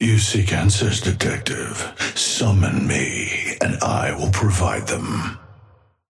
Amber Hand voice line - You seek answers, detective.
Patron_male_ally_atlas_start_01.mp3